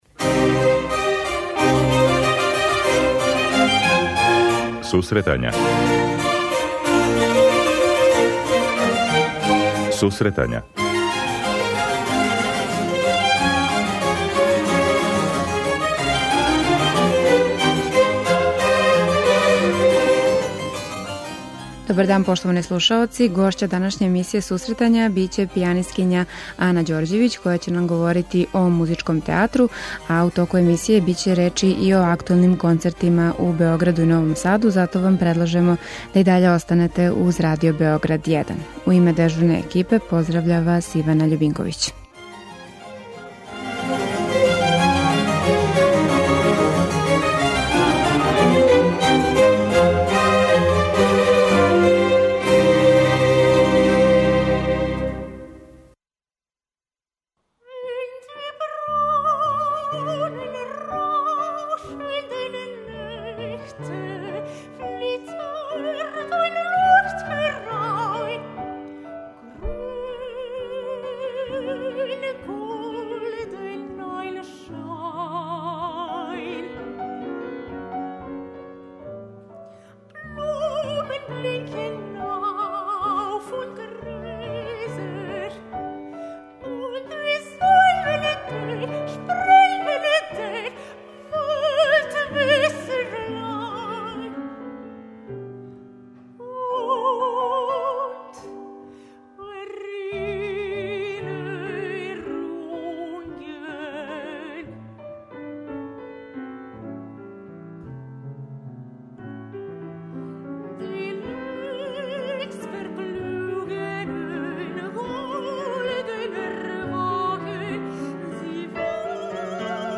У емисији ће бити речи о концертима који су обележили ову музичку недељу. Гошћа у студију